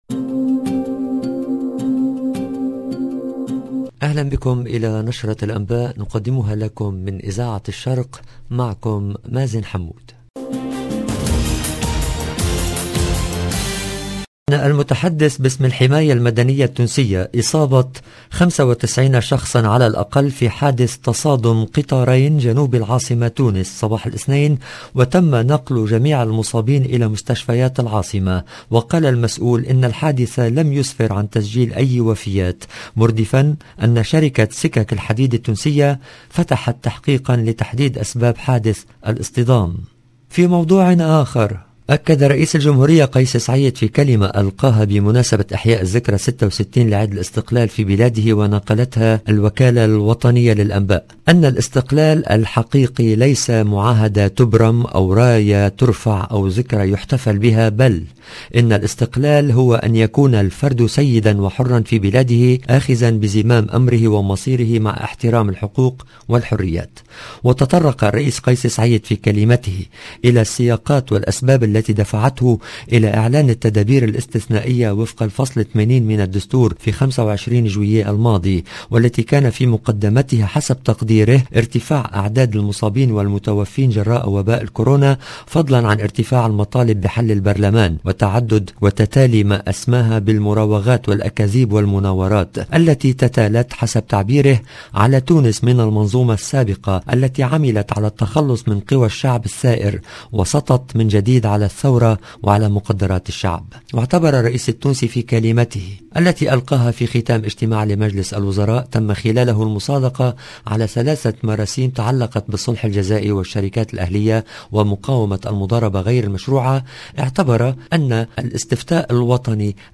LE JOURNAL DU SOIR EN LANGUE ARABE DU 21/03/22